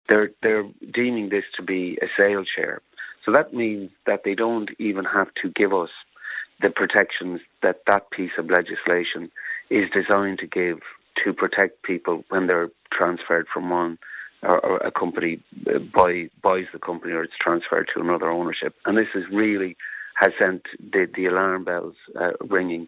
Speaking on Kildare Today, he warned that the terms and conditions of employees, including their right to collectively bargain, would not be guaranteed under the share deal structure.